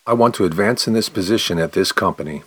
ESL/Dialogue practice: job interview
Spoken fast:
10_response_fast.mp3